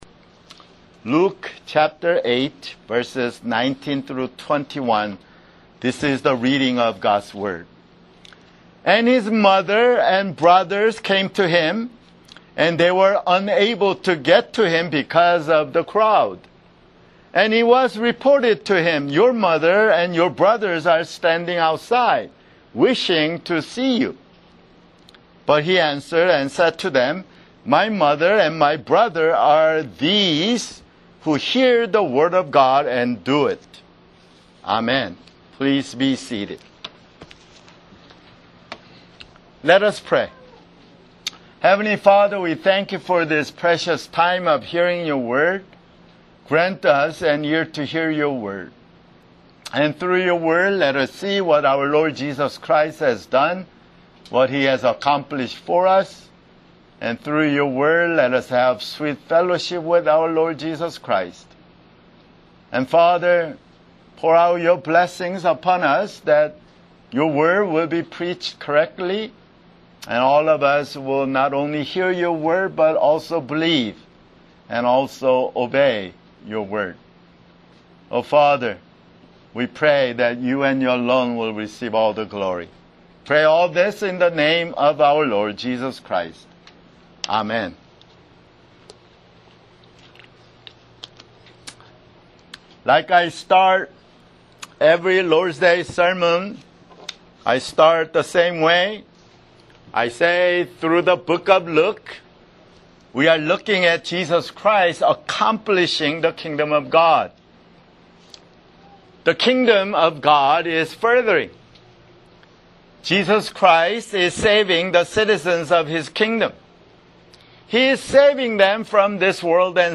[Sermon] Luke (58)